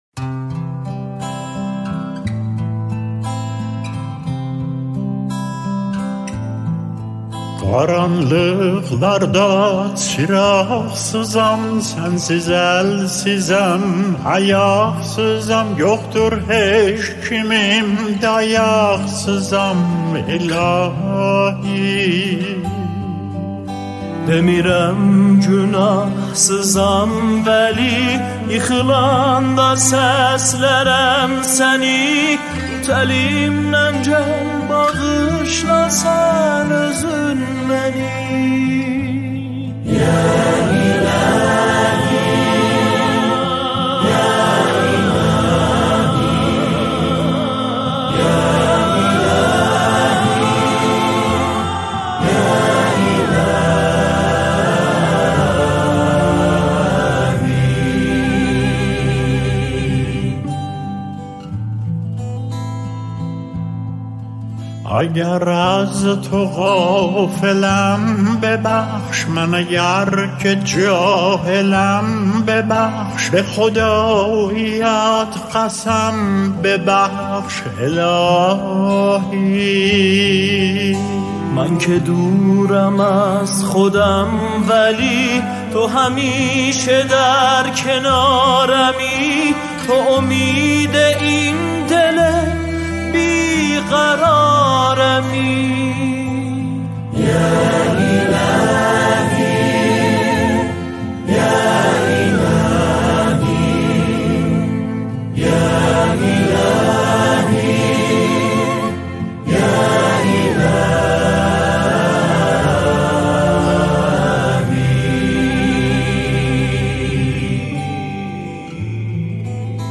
نماهنگ همخوانی